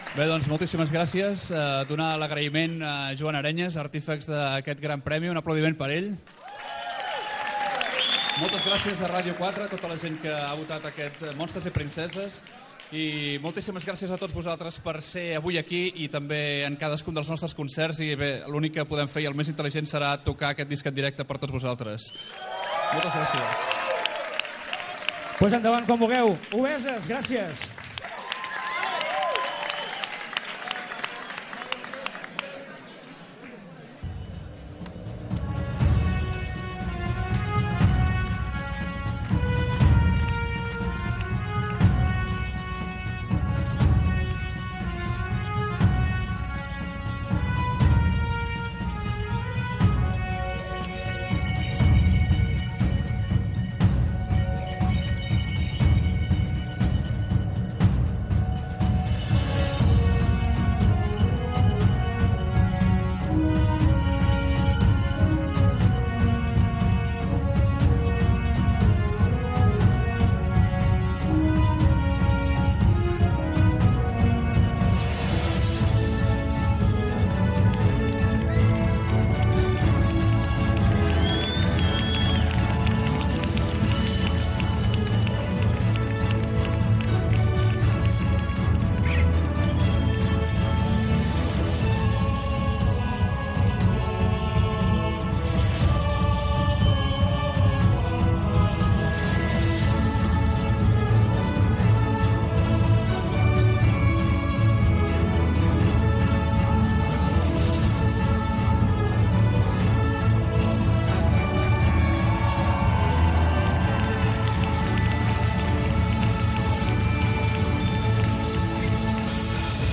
Acte de lliurament del Disc Català de l'any al grup Obeses a la Fàbrica Dam de Barcelona
Musical